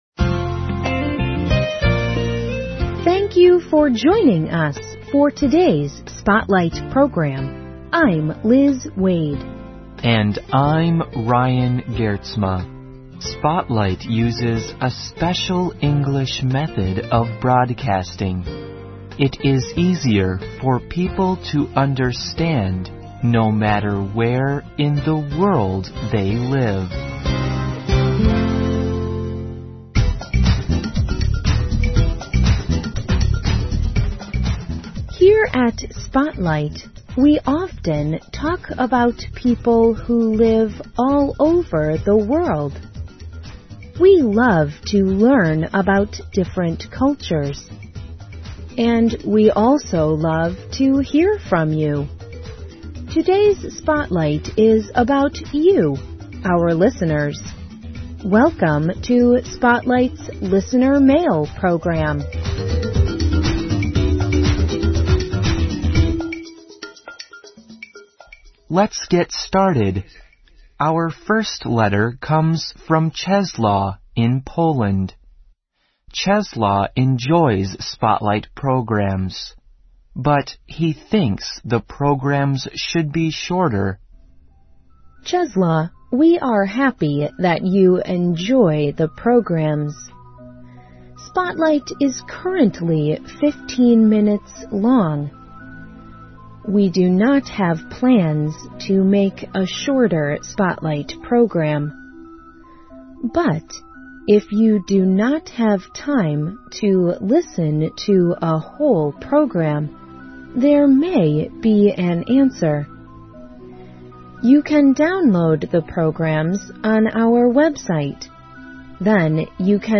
Listener Mail Program